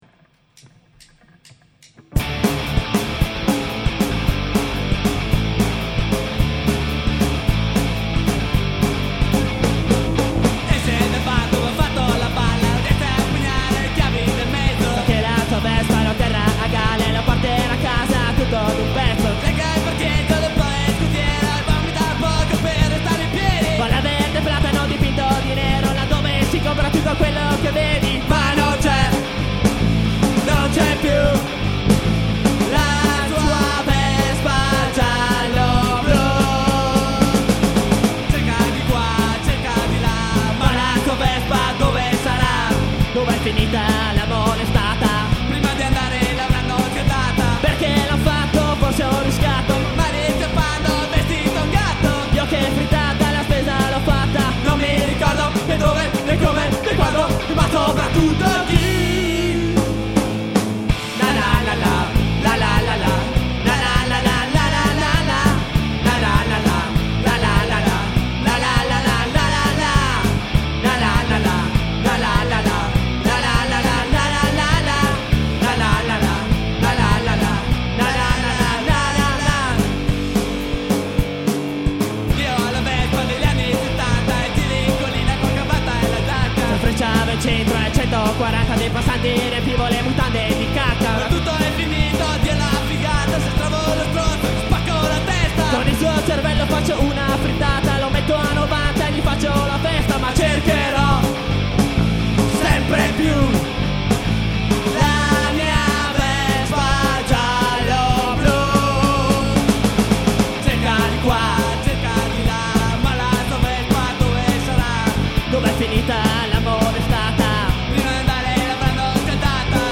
Noi facevamo punk-rock puro, con cover di Punkreas, Nofx, Sex Pistols, Offspring ... e via dicendo.
Abbiamo anche registrato un inedito ad un Rock Contest ....
Quello che sparla alla fine della canzone e quello che fa la seconda voce sono io.